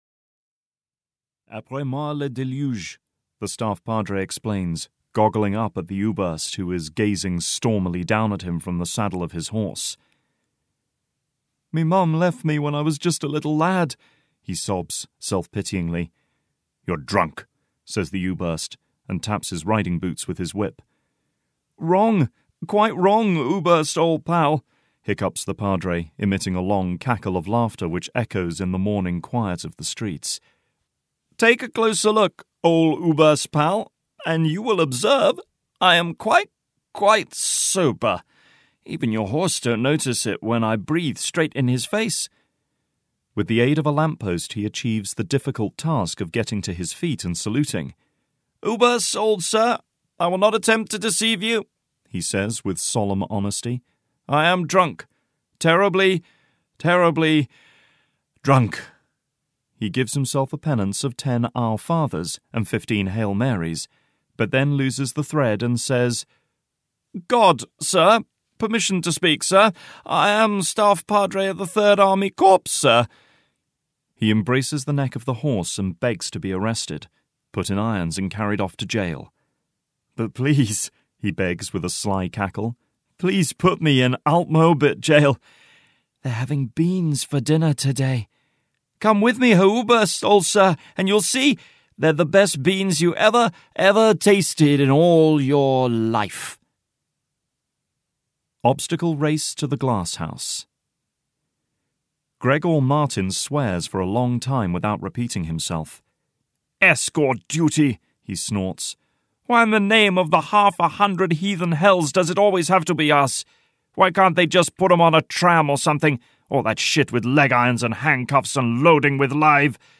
OGPU Prison (EN) audiokniha
Ukázka z knihy